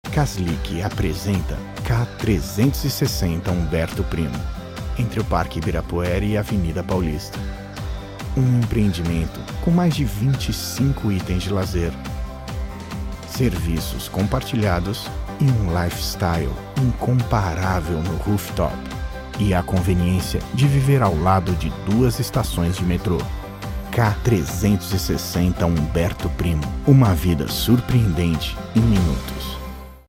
Tenho um home studio de nível profissional.
Jovem adulto